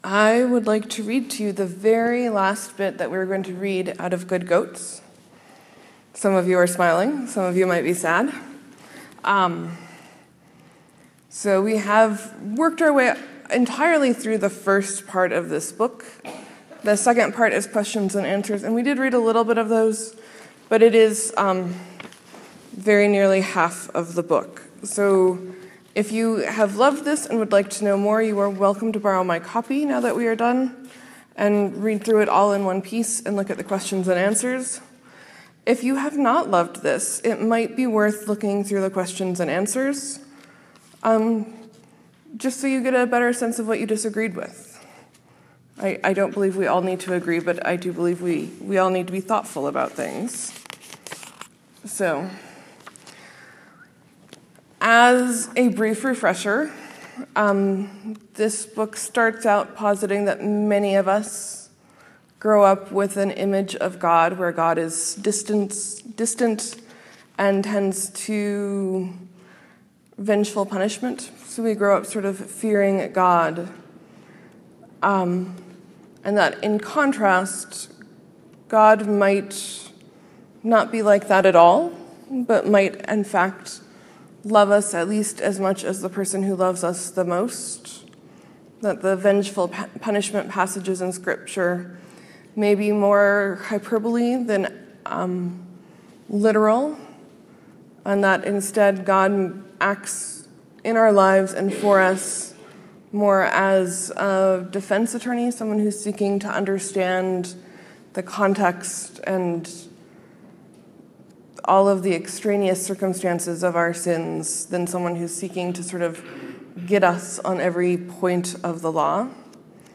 Sermon: In a first, I sing during the sermon.